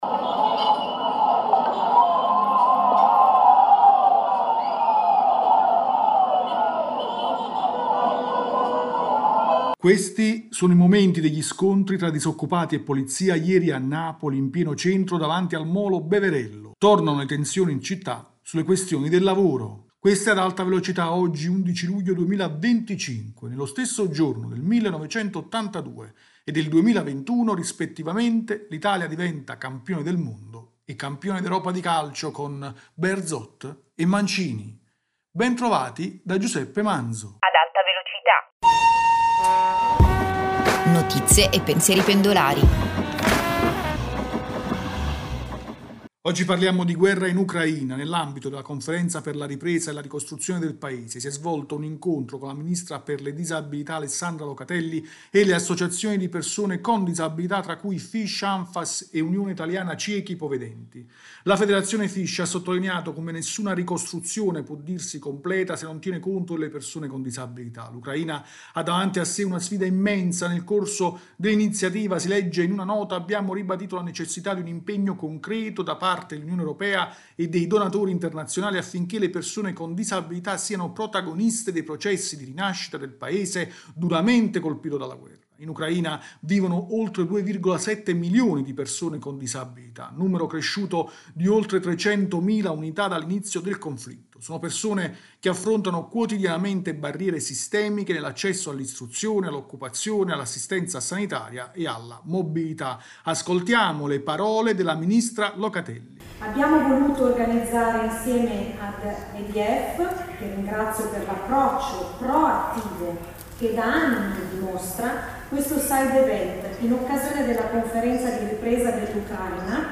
Ascoltiamo le parole della ministra Locatelli.